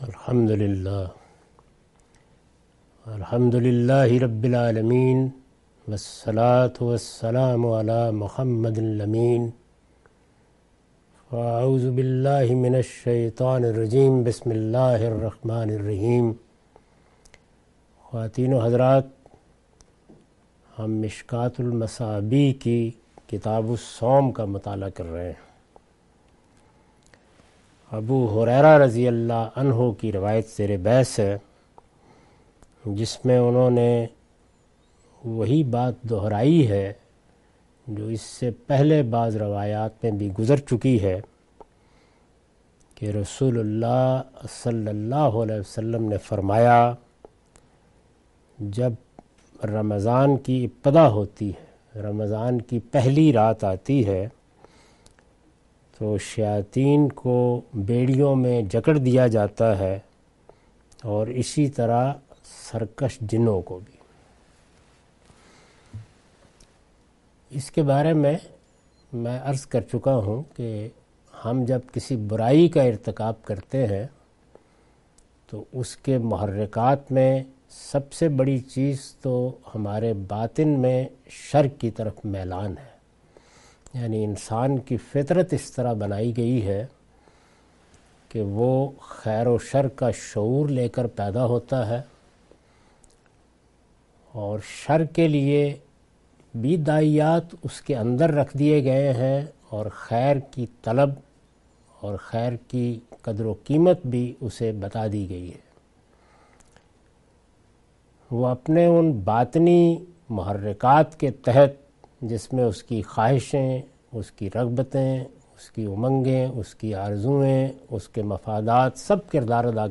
Sayings of the Prophet , Questions & Answers